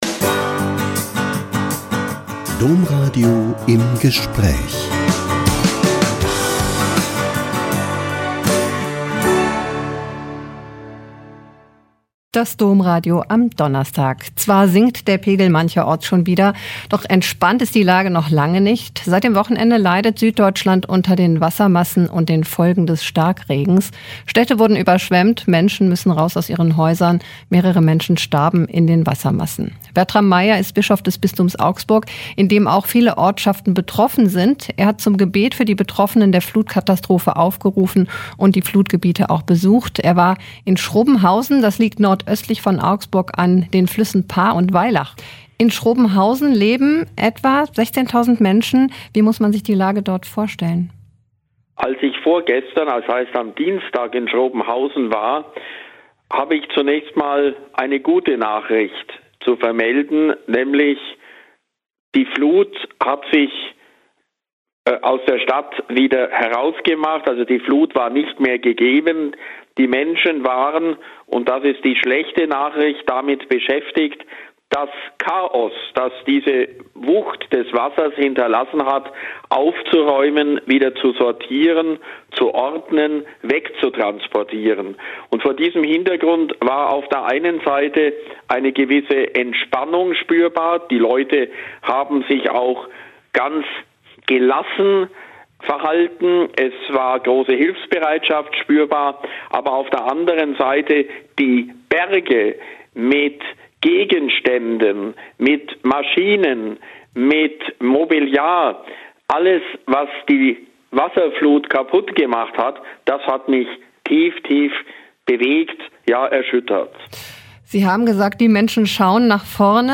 Bischof Meier besucht Hochwassergebiet in seinem Bistum - Ein Interview mit Bischof Bertram Meier (Bischof von Augsburg)